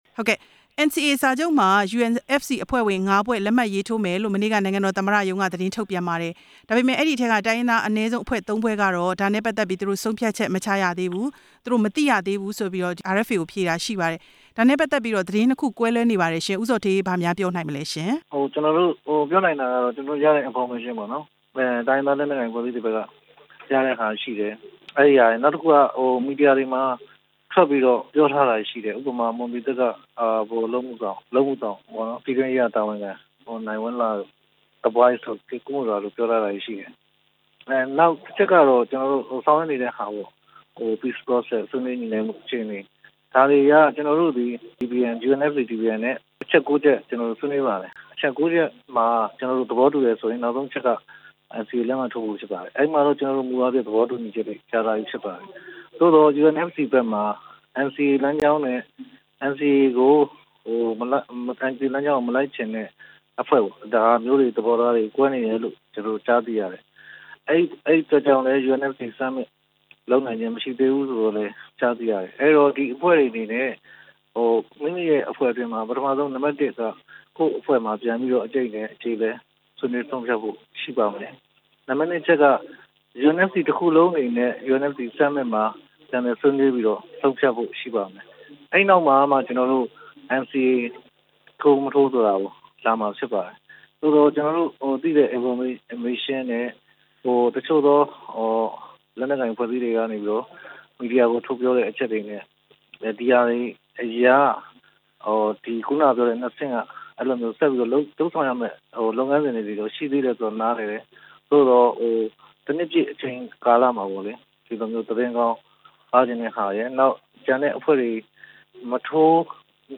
NCA မှာ ၅ ဖွဲ့ လက်မှတ်ရေးထိုးရေးကွဲလွဲနေမှု သမ္မတရုံးနဲ့ မေးမြန်းချက်